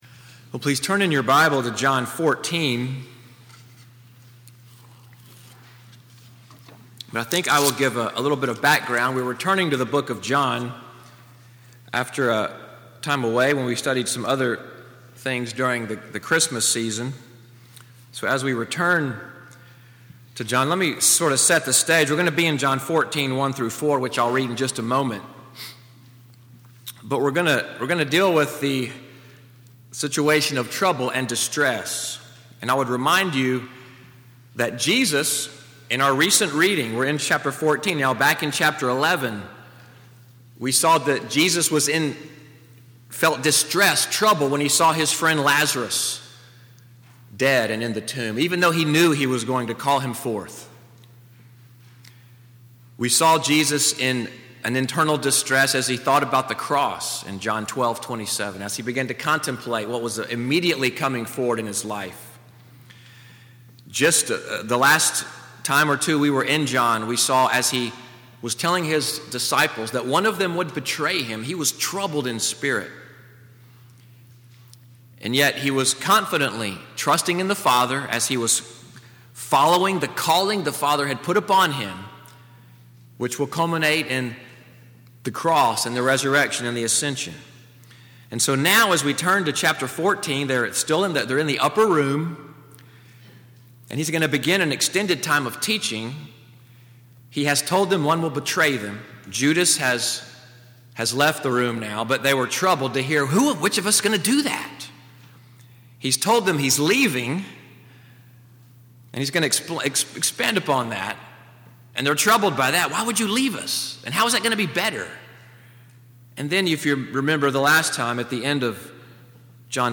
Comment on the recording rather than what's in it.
MORNING WORSHIP at NCPC-Selma, audio from the morning sermon, “Heavenly Homemaking,” January 21, 2018